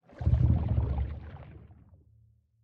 Minecraft Version Minecraft Version 25w18a Latest Release | Latest Snapshot 25w18a / assets / minecraft / sounds / block / bubble_column / whirlpool_ambient5.ogg Compare With Compare With Latest Release | Latest Snapshot
whirlpool_ambient5.ogg